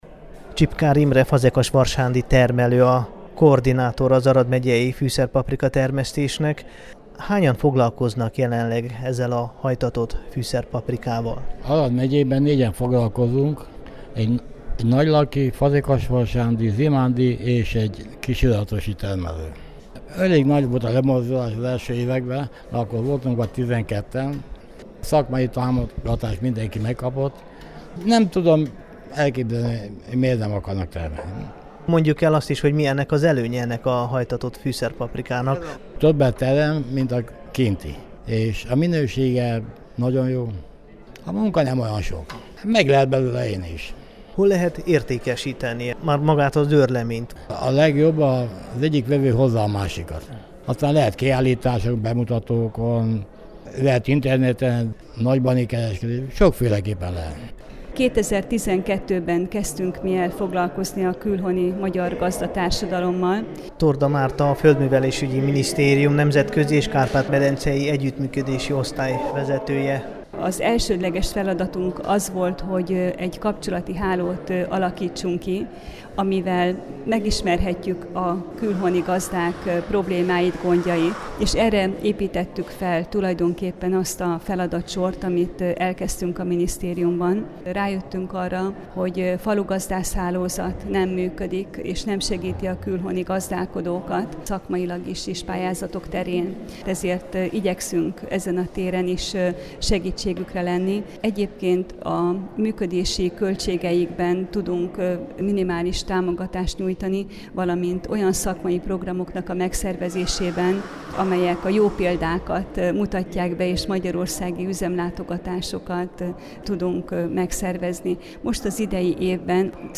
A múlt csütörtökön szakmai találkozót szervezett az Arad Megyei Magyar Gazdák Egyesülete.
fuszerpaprika_talalkozo_kisiratoson.mp3